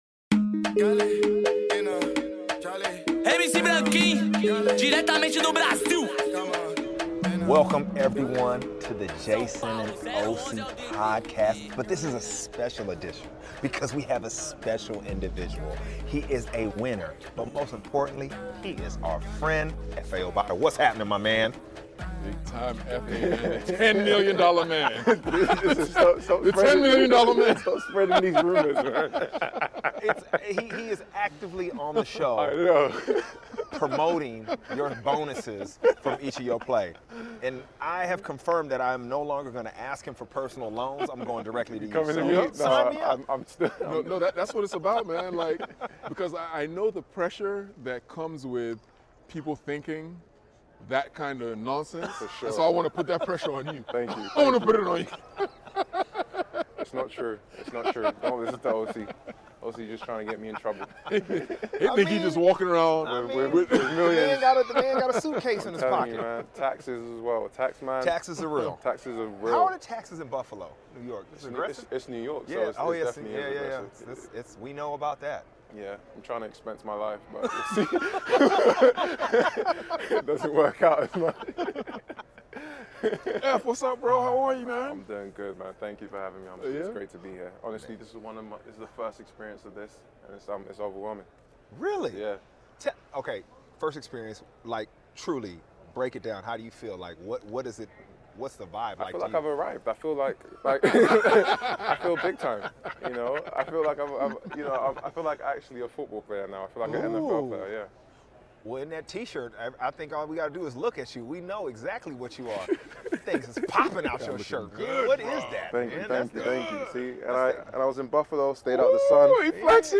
Watch on the NFKUK YouTube Channel, as Efe Obada sits in with the boys at the most colourful set at the Los Angeles Super Bowl Media Centre.